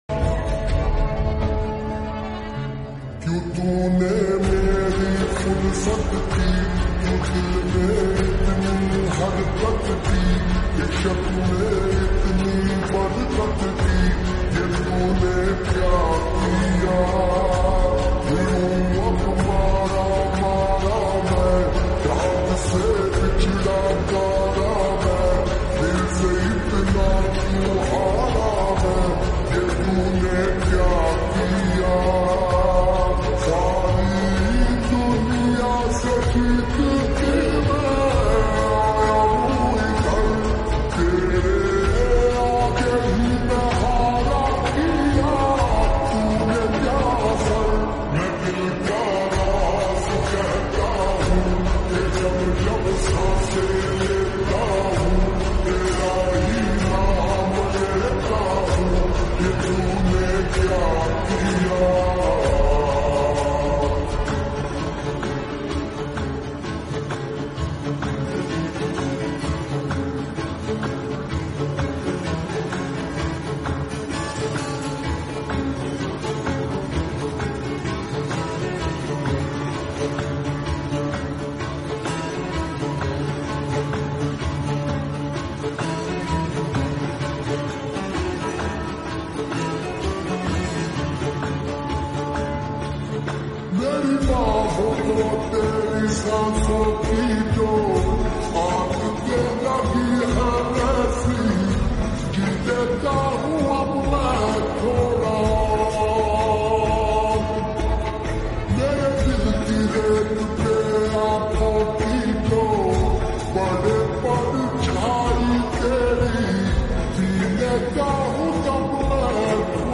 SLOWED AND REVERB FULL SONG SLOWED ULTRA HD